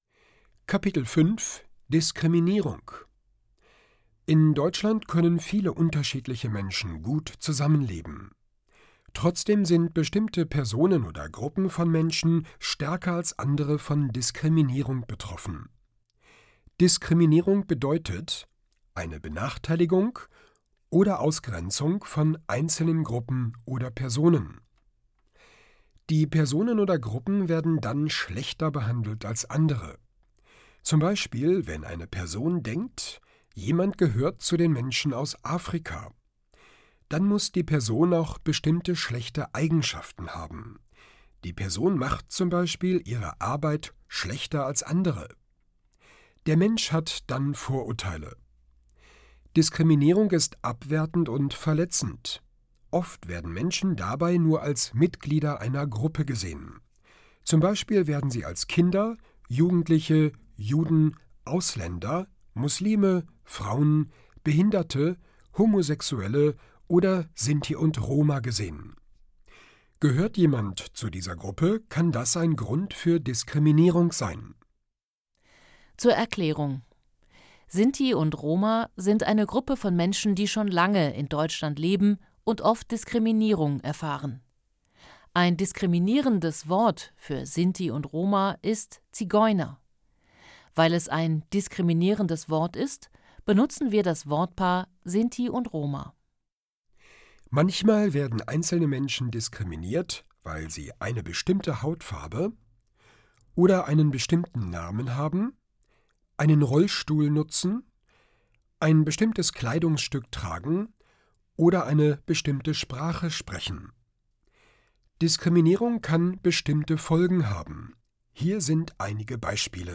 Kapitel 5: Diskriminierung Hörbuch: „einfach POLITIK: Zusammenleben und Diskriminierung“
• Produktion: Studio Hannover